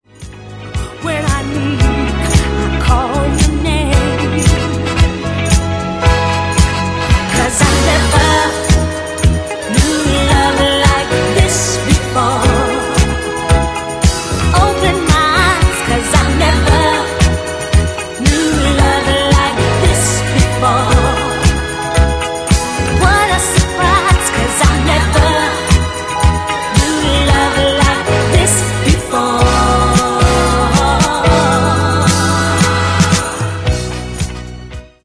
Genere:   Pop | Rock | Dance